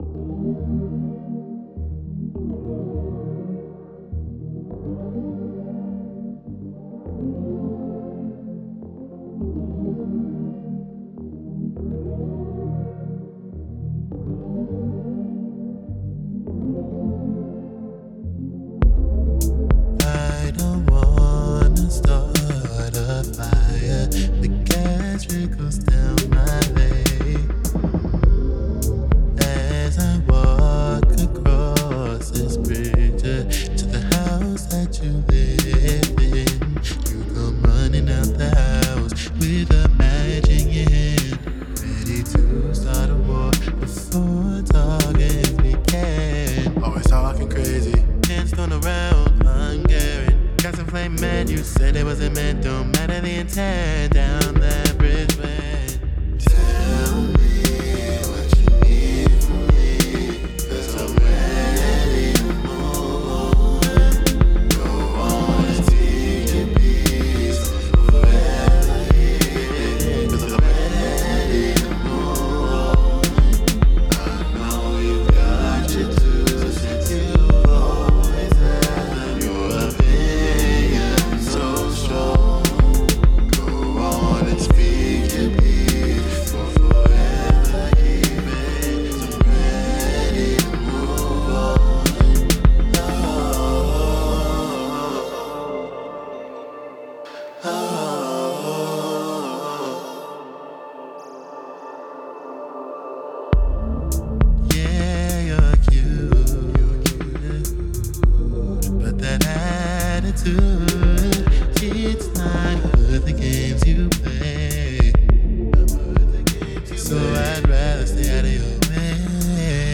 I acheieved this by EQ’ing out some of the low end in the pad and boosting the highs while doing the opposite to the bass.
Next, I worked on making sure there was separation between the kick and the bass by cutting out the fundamental frequency of the kick in the bass and slightly boosting it in the kick to make it stand out in the mix.
There were some piano sounds and a glockenspiel that plays in the chorus that I believe sounded too simple, so I changed their sounds to be more atmospheric and distorted to make them sound more distinct. I added a couple of different distortion plugins first to achieve the correct mix and then added reverb to take away some of the bite.
The main challenge with the song was attempting to mix the low lead vocals with the low backing track behind it.
Lastly, I wanted the final chorus to come back in with a lot of wet reverb and to sound larger than life.